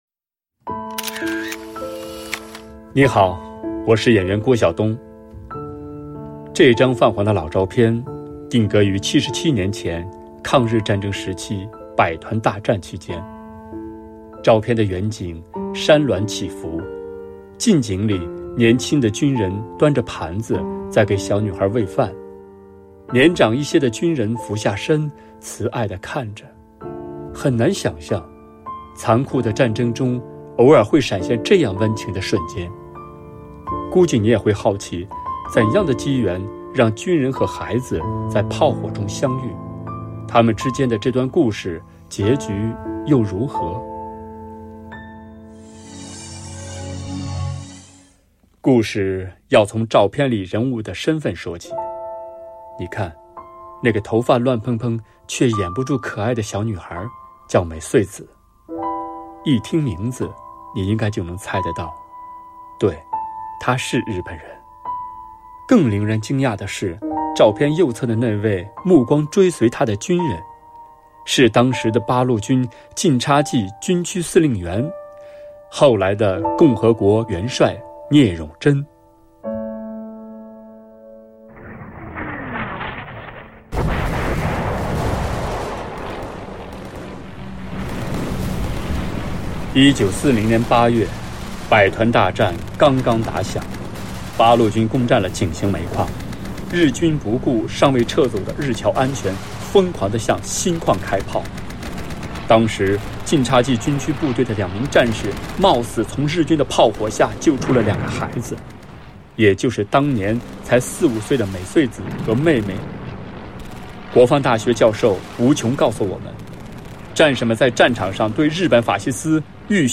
关键词:CNR,中国之声,大国军藏,收藏,解说,军事
《大国军藏》十件珍品的声音导览。